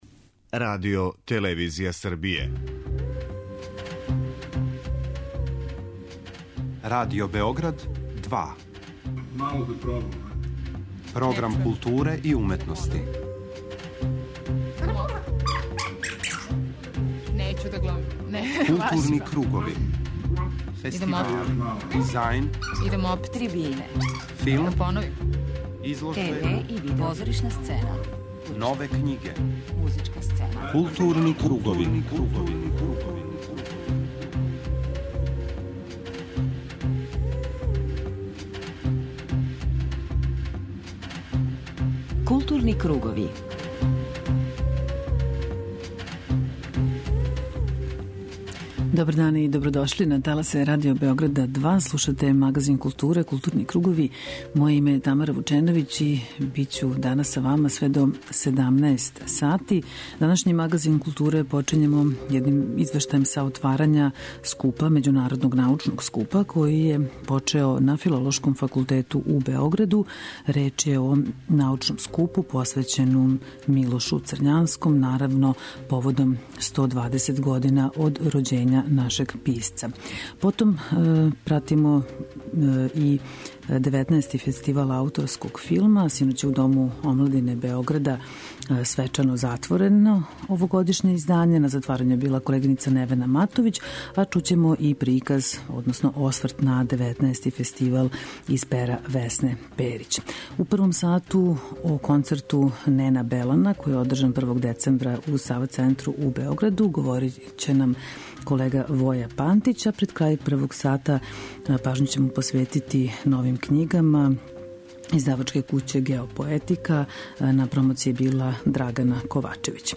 а у данашњој емисије чућете делове излагања са овог скупа.
преузми : 53.14 MB Културни кругови Autor: Група аутора Централна културно-уметничка емисија Радио Београда 2.